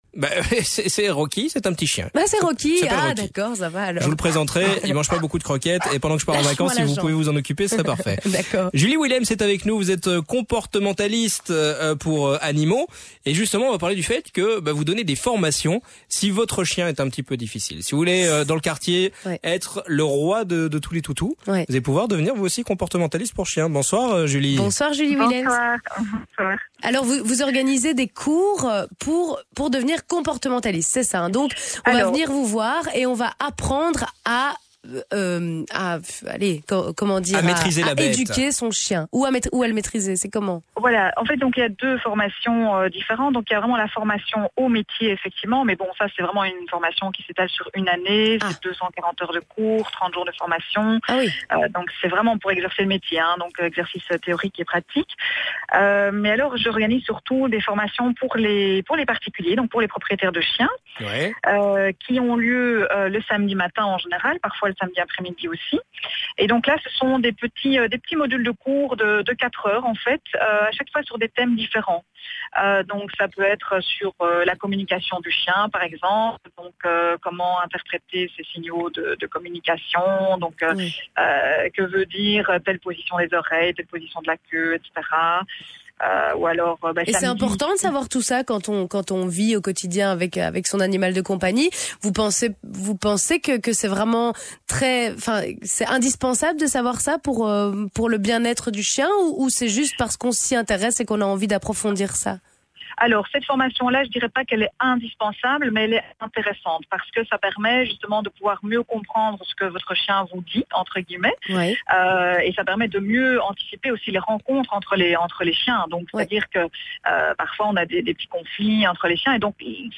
Interview radiophonique